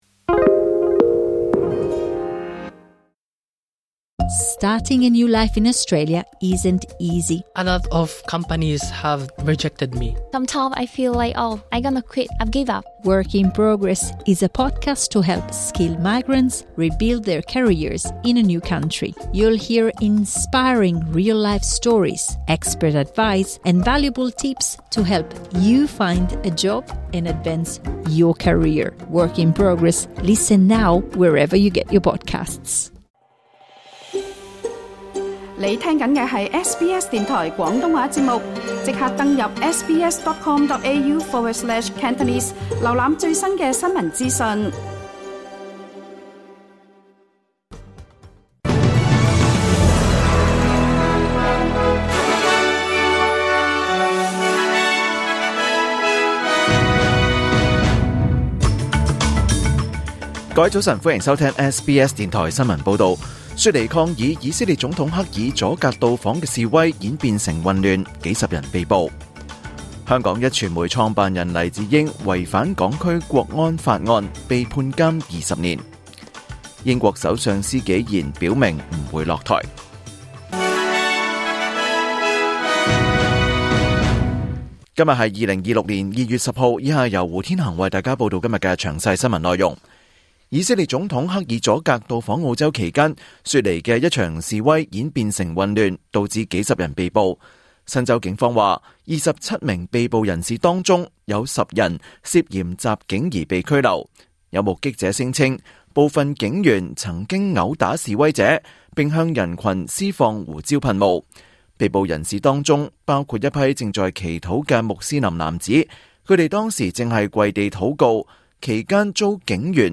2026年2月10日SBS廣東話節目九點半新聞報道。